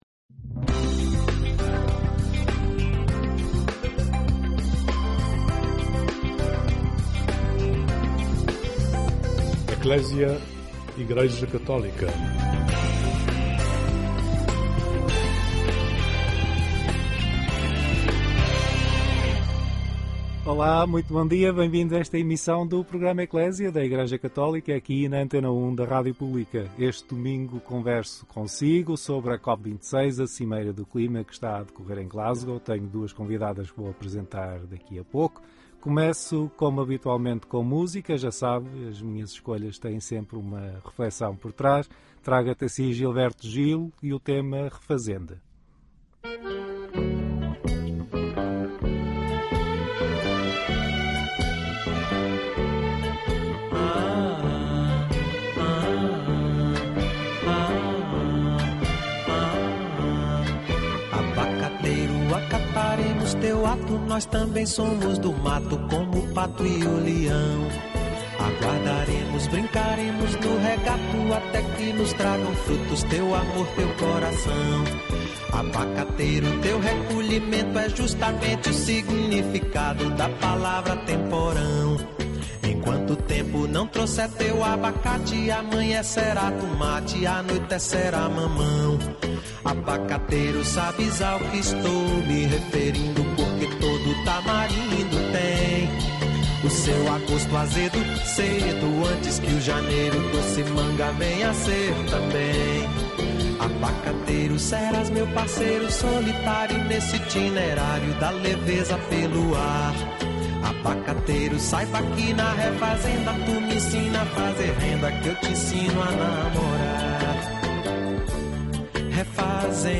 Em conversa com o Programa ECCLESIA, assumem que levam na bagagem “inquietação e esperança”, porque ainda há tempo de assumir compromissos que preservem o futuro da humanidade.